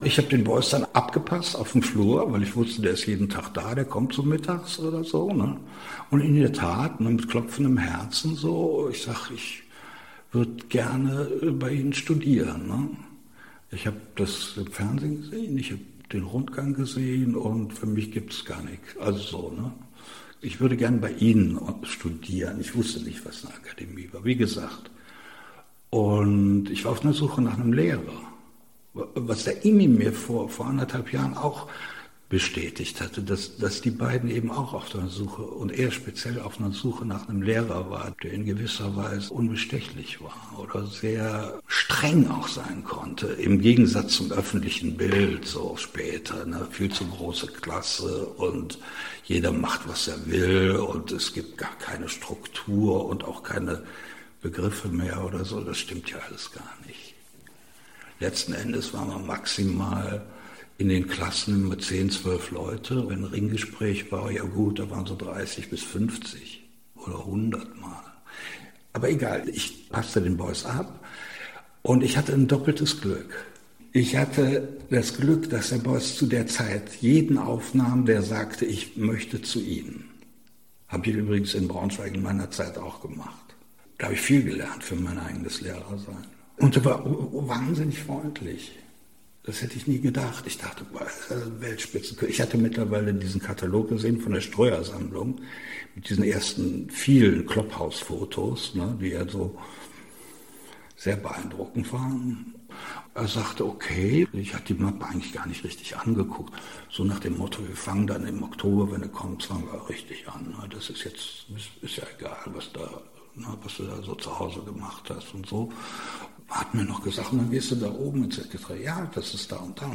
Interview Audioarchiv Kunst: Walter Dahn – Bewerbung an der Akademie